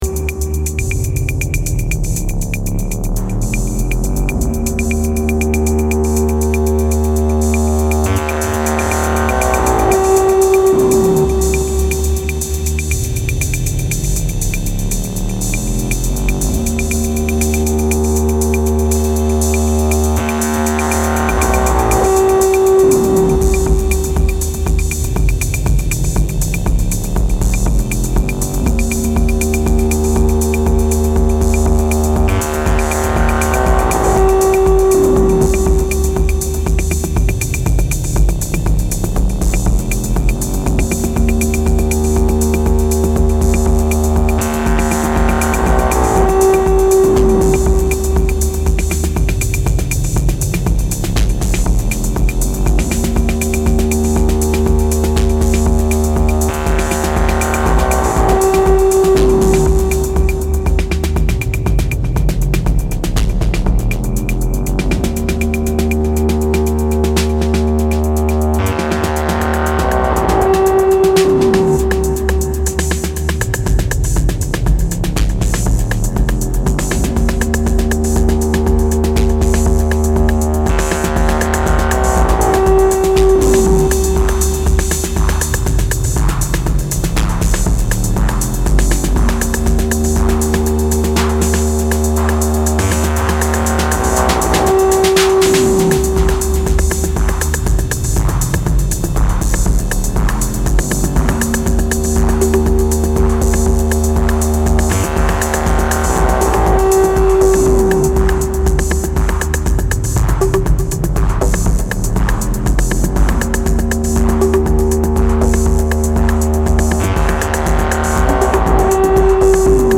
Electronic Music